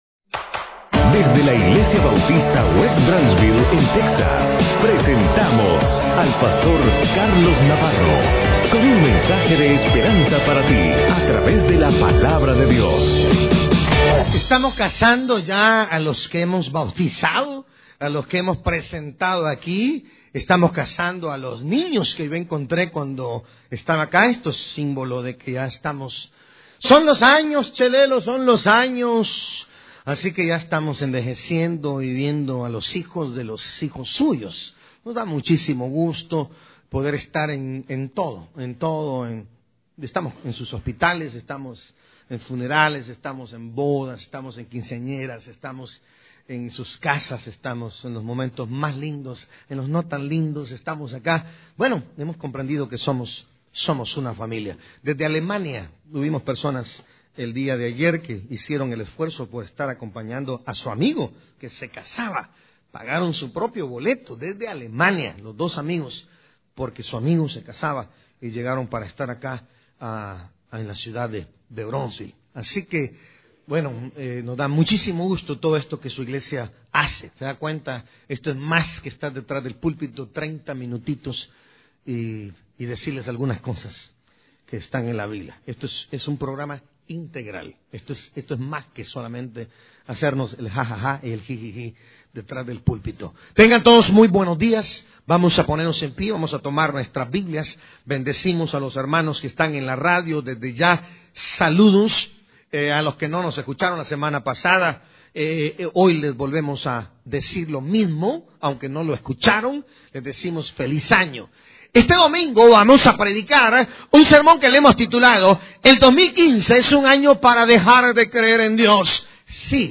Predicador